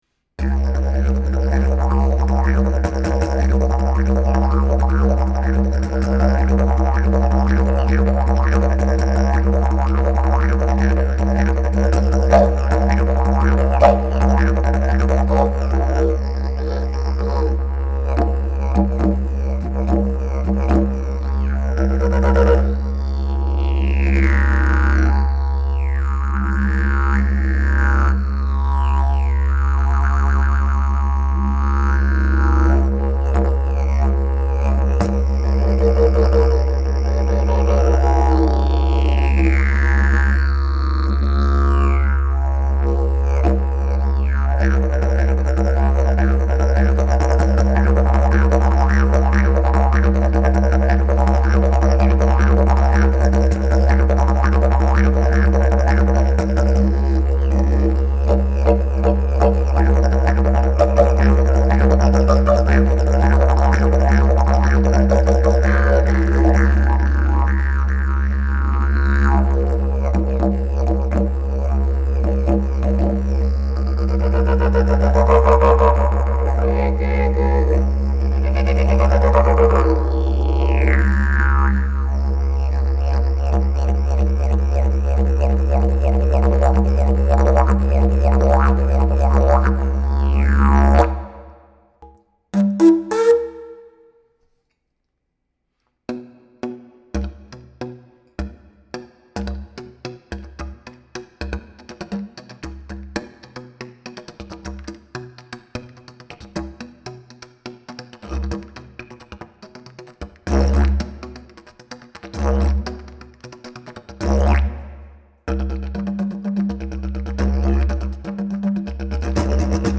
Key: C# Length: 52" Bell: 3.25" Mouthpiece: Red Zebrawood, Black Walnut Back pressure: Very strong Weight: 3.8 lbs Skill level: Any
Didgeridoo #618 Key: C#